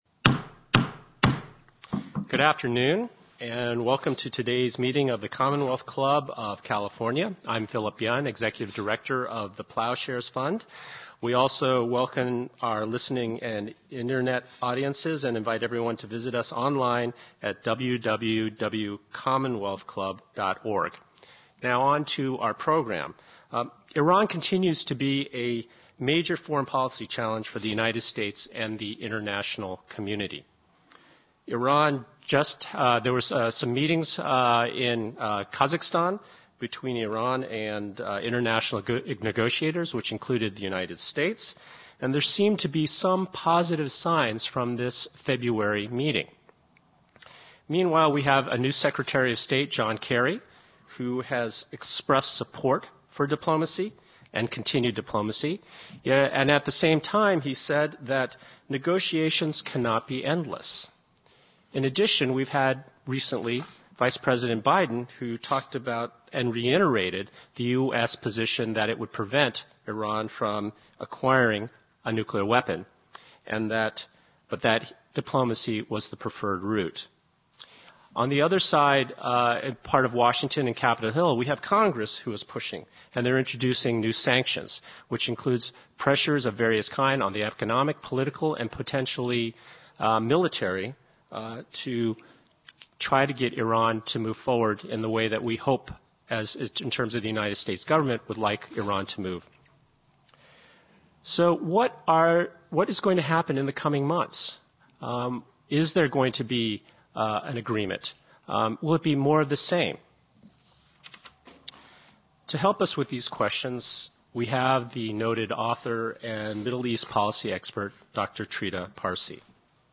Moderator As we enter a new year and a reconfigured Obama administration, our relations with Iran loom large. Parsi, a noted expert on U.S. and Iranian relations and the Middle East, will discuss his latest book, A Single Role of the Dice, which received the Foreign Affairs Award for best book of 2012 on the Middle East.